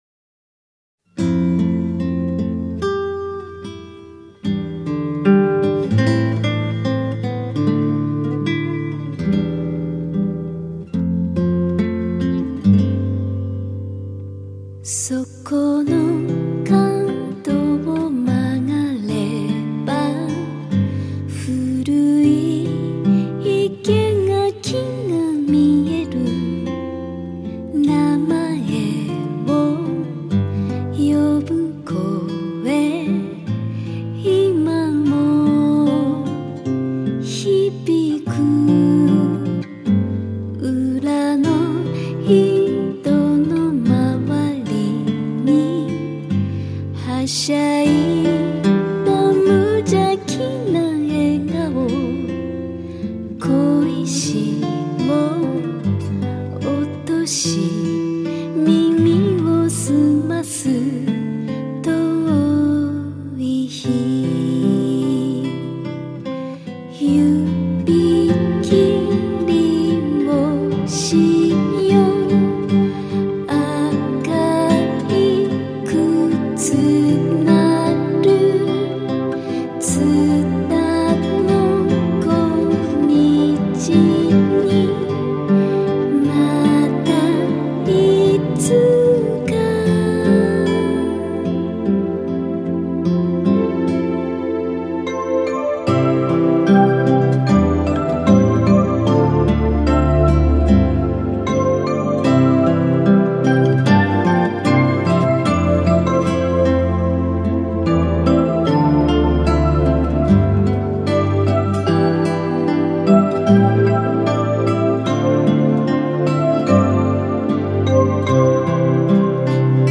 声を一つの楽器として積み重ねて作る浮遊感あるサウンドは他にはないオリジナリティ溢れるもの。